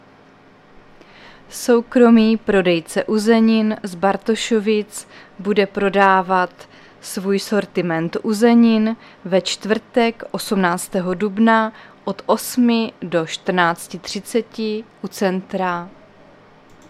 Záznam hlášení místního rozhlasu 17.4.2024
Zařazení: Rozhlas